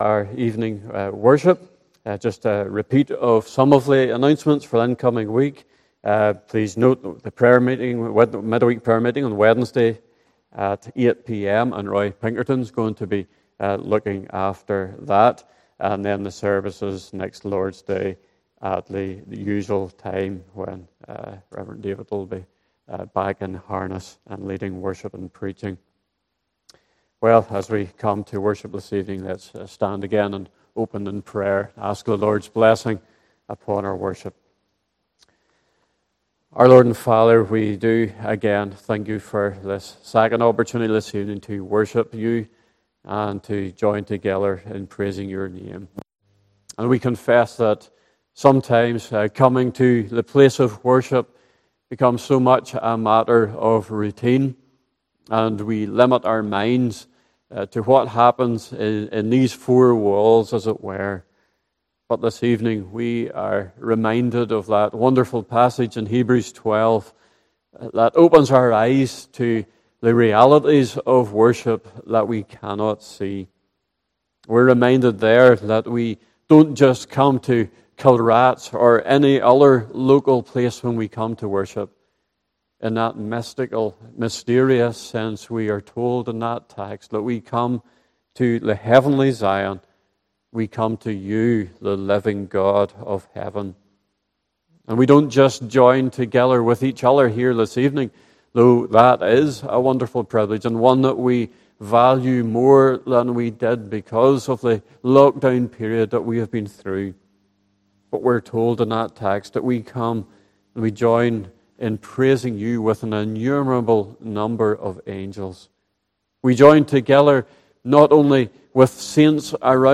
Service Type: Evening Service